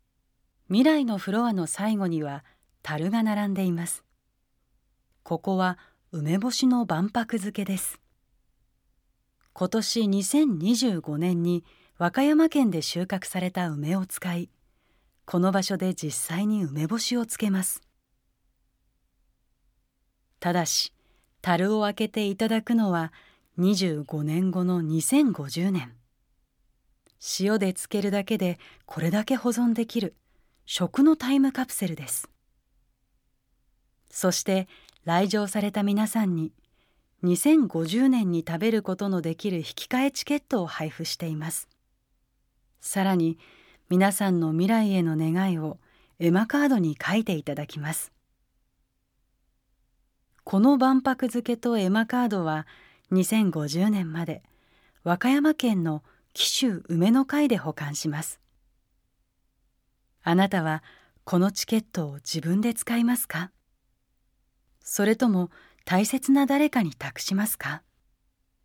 音声ガイドナレーター：宇賀なつみ